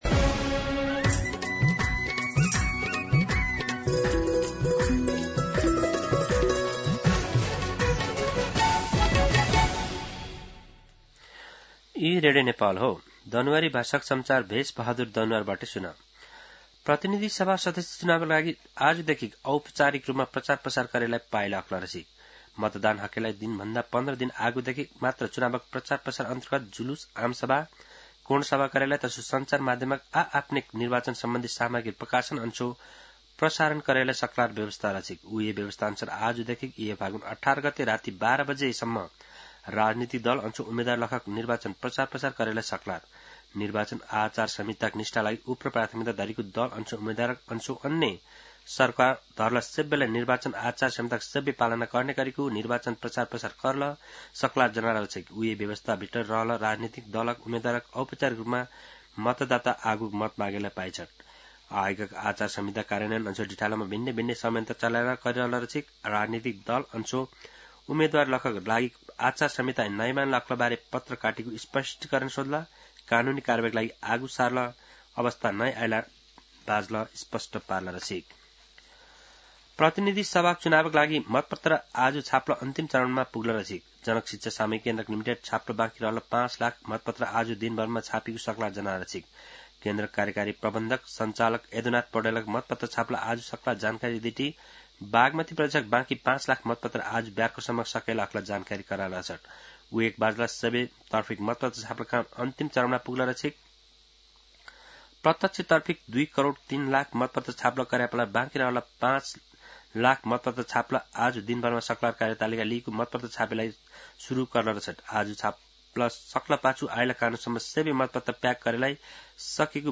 दनुवार भाषामा समाचार : ४ फागुन , २०८२
Danuwar-News-11-4.mp3